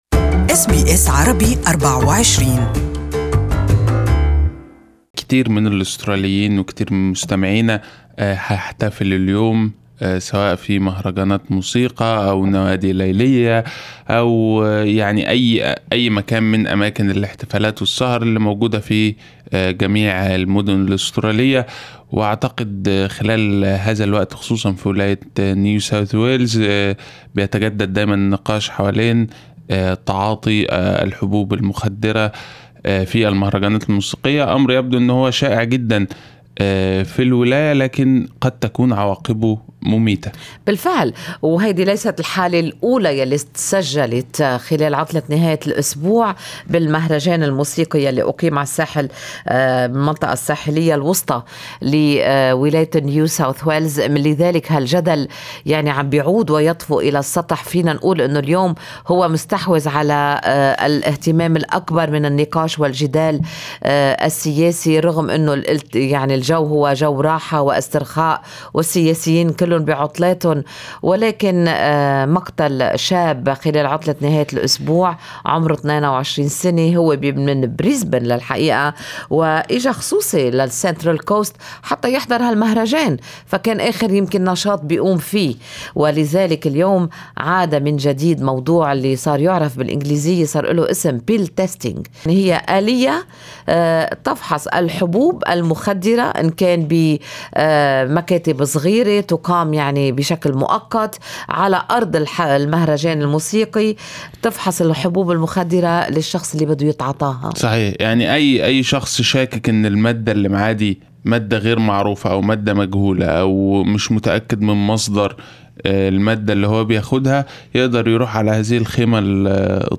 استمعوا إلى تقرير عن جدل اختبار الحبوب المخدرة في الرابط أعلاه إقرأ المزيد جرعة زائدة تسرق حياة شاب في حفل في سيدني كيف يمكن الحصول على المساعدة للتخلص من الإدمان على الكحول والمخدرات؟